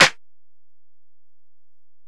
Snare (6).wav